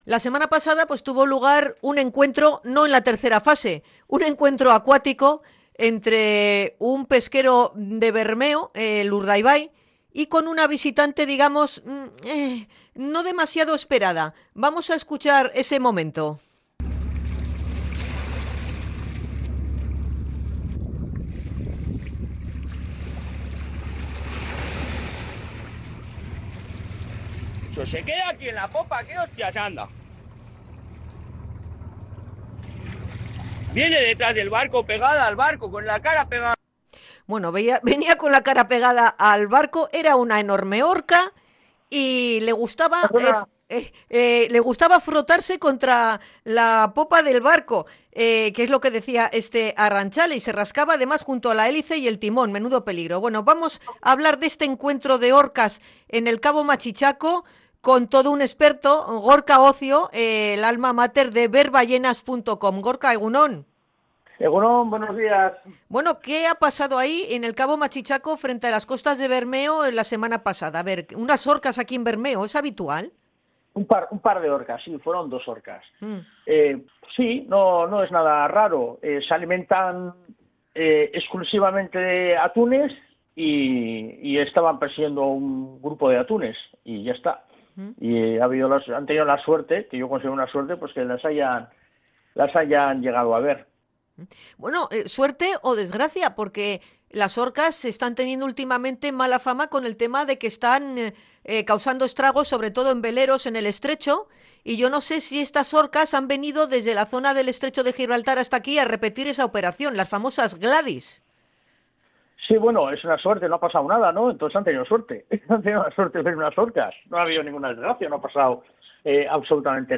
INT.-GLADYS-EN-BERMEO.mp3